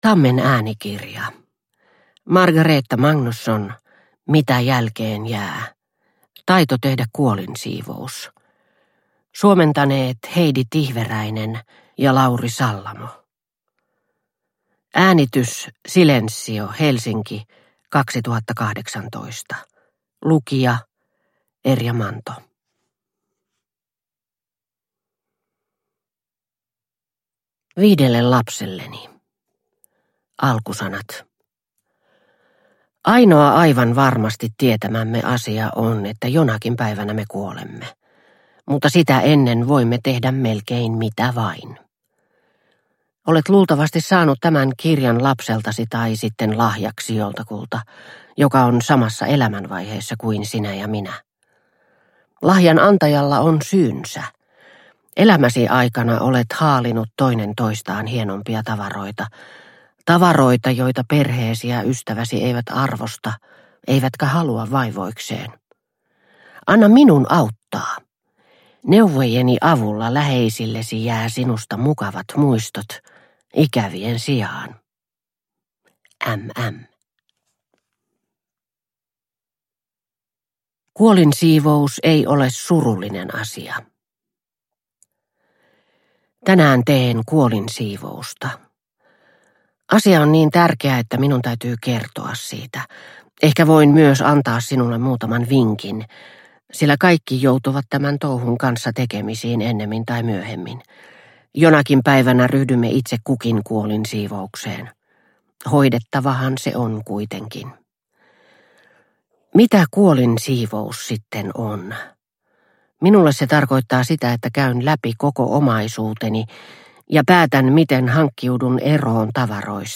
Mitä jälkeen jää - Taito tehdä kuolinsiivous – Ljudbok – Laddas ner